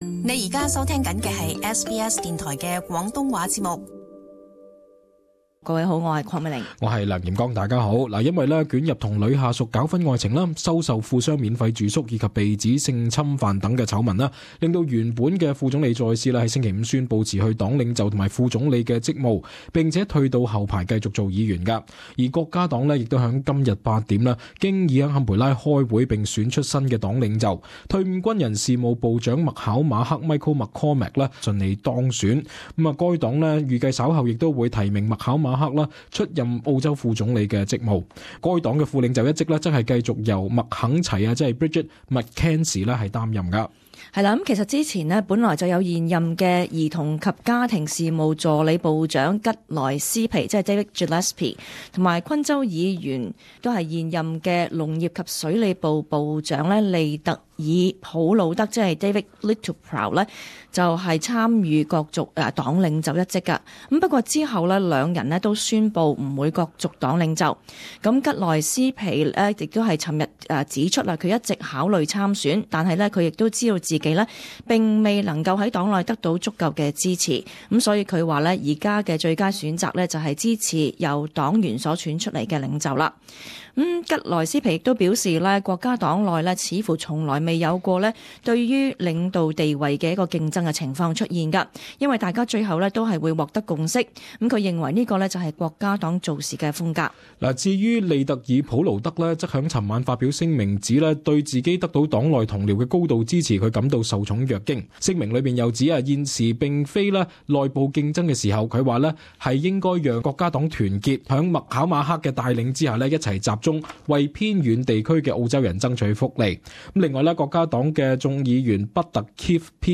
【時事報導】麥考馬克順利當選為國家黨新領袖